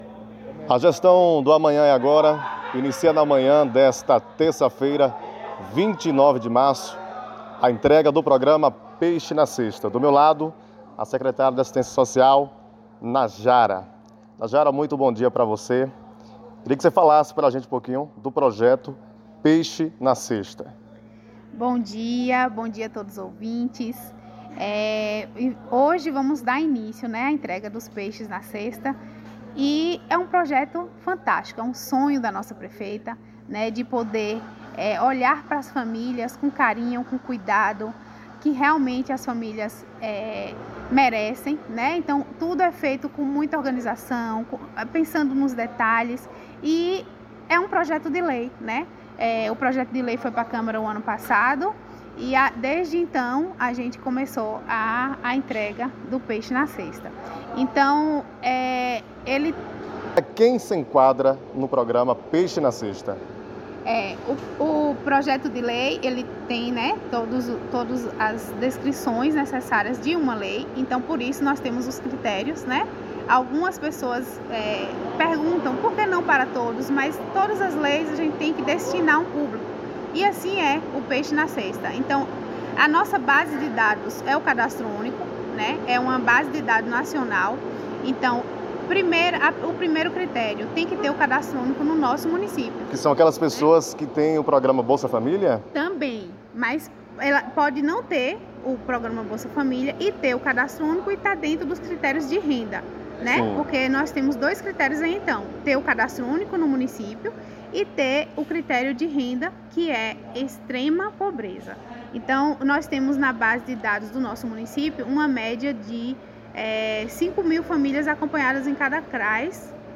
A redação da Rádio Brilhante entrevistou nesta manha de terça feira a secretária de Assistência Social do município que deu detalhes sobre o programa e a distribuição.
Acompanhe a entrevista com a secretária de Assistência Social Najara Xavier
entrevista-com-a-secretaria-de-Assistencia-Social-Najara-Chavier.mp3